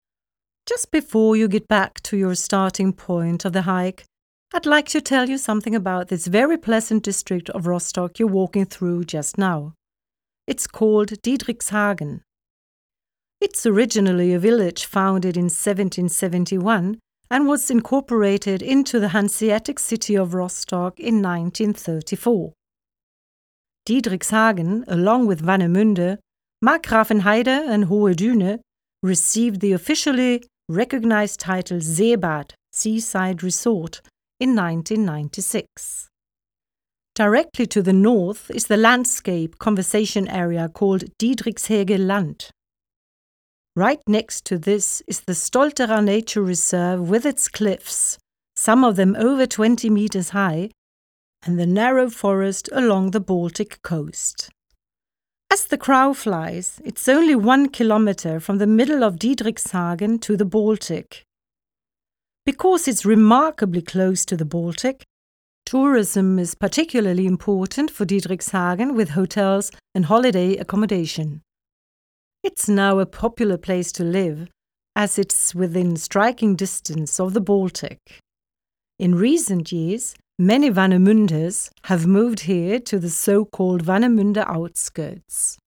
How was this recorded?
Responsible for the sound recordings: Sound studio at Stralsund University of Applied Sciences.